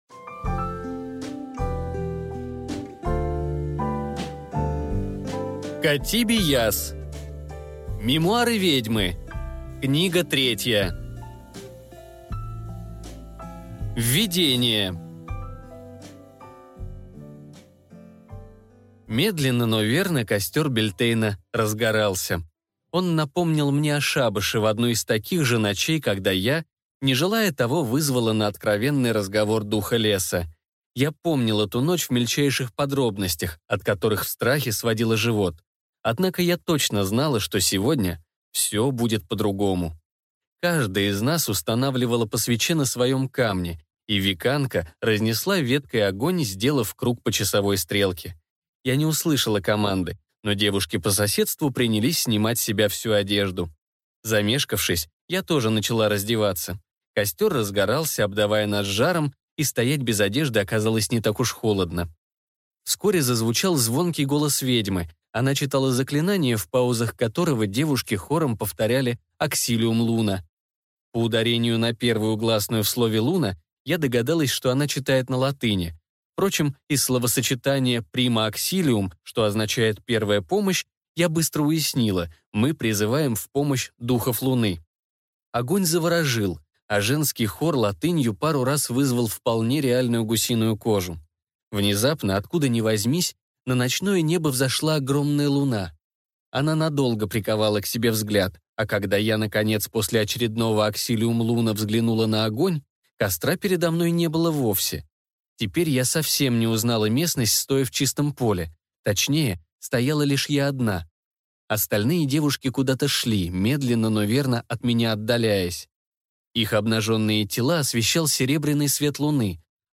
Аудиокнига Мемуары Ведьмы 3 | Библиотека аудиокниг